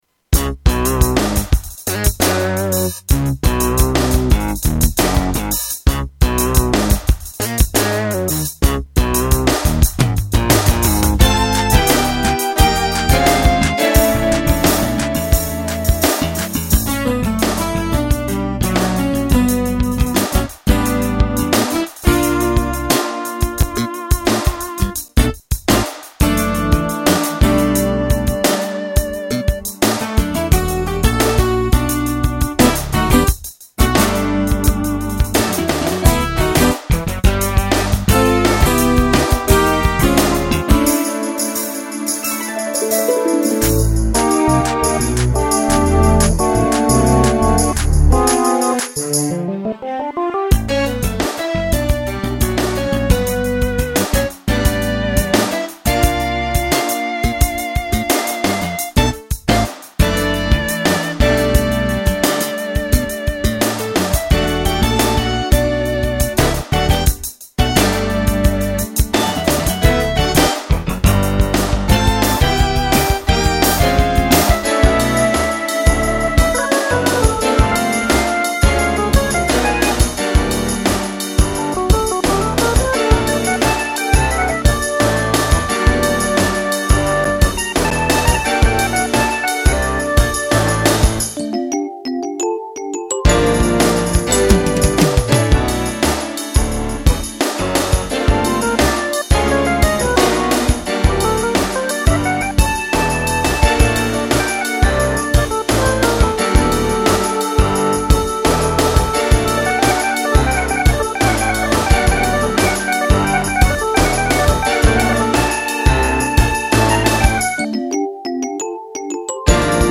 Category: Sound FX   Right: Personal
Tags: Kurzweil K2500 Kurzweil K2500 clips Kurzweil K2500 sounds Kurzweil Synthesizer